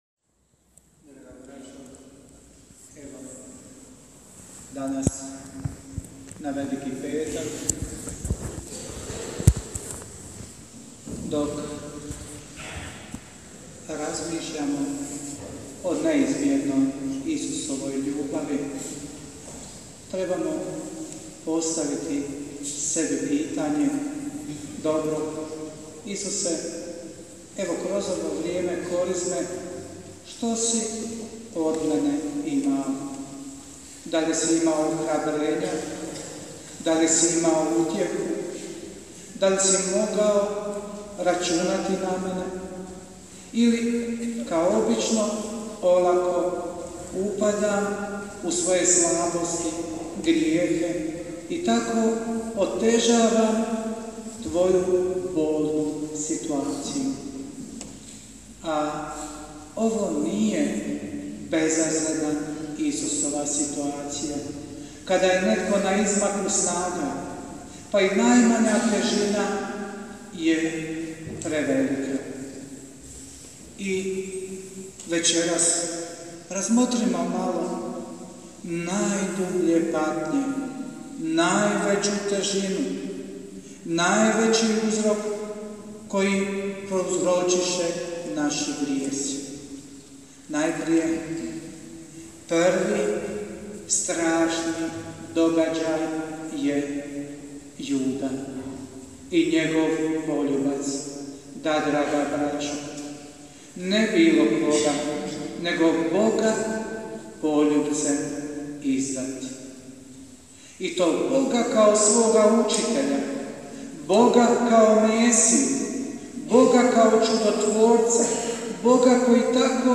PROPOVIJED: